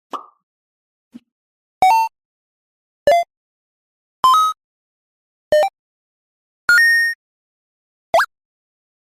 Retro 8-Bit Loop
#8bit#retro#chiptune#game
572_retro_8-bit_loop.mp3